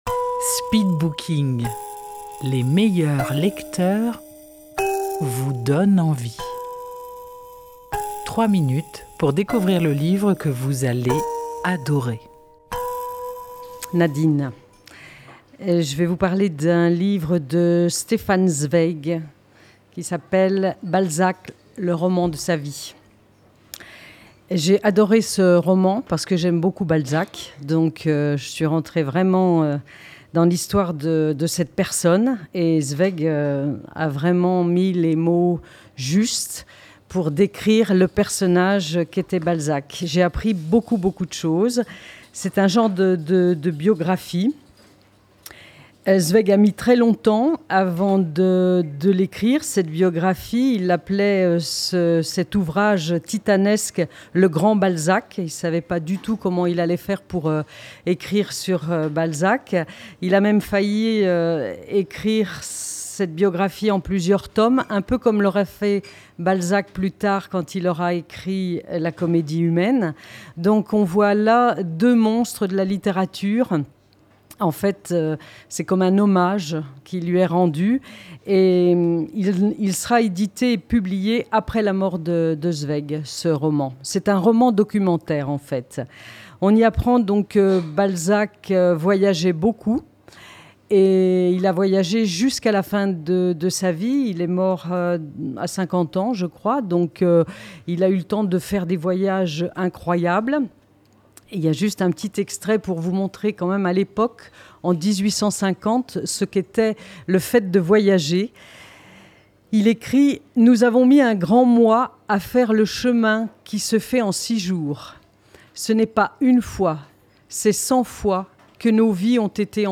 Enregistré en public au Bar & Vous à Dieulefit.